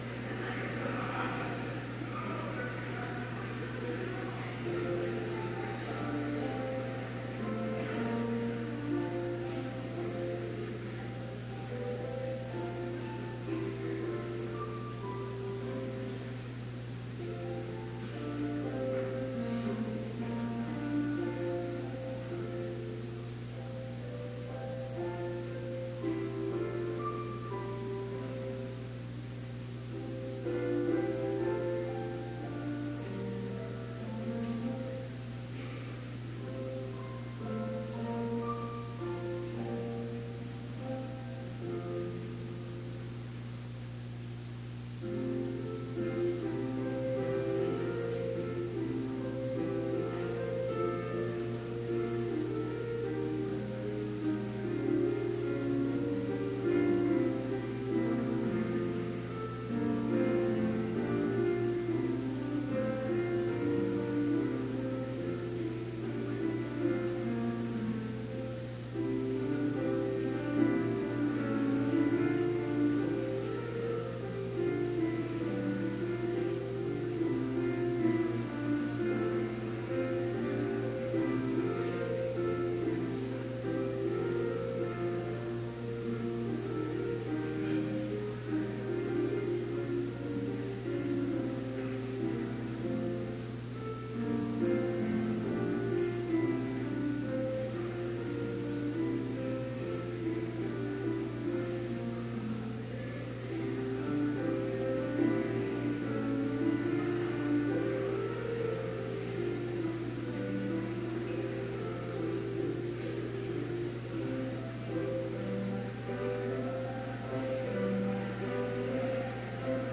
Complete service audio for Chapel - February 2, 2018
Watch Listen Complete Service Audio file: Complete Service Sermon Only Audio file: Sermon Only Order of Service Prelude Psalm 27: The Lord Is My Light Duet: The Lord is my light…